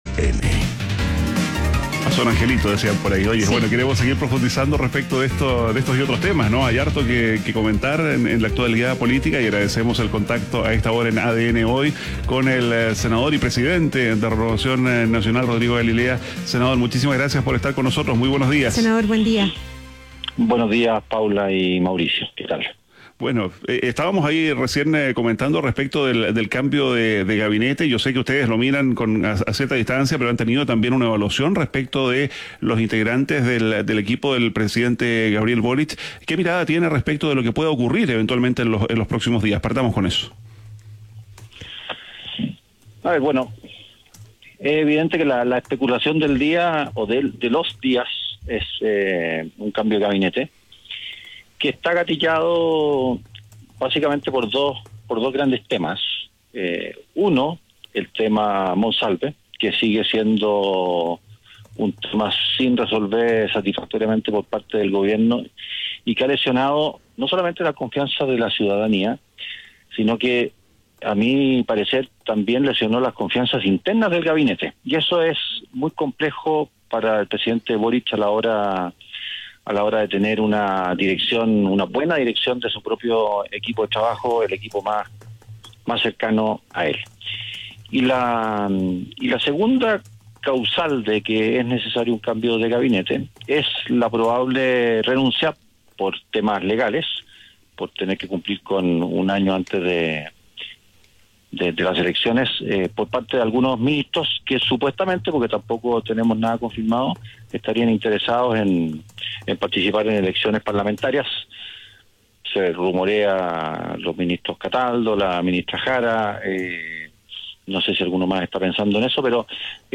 ADN Hoy - Entrevista a senador y presidente de Renovación Nacional, Rodrigo Galilea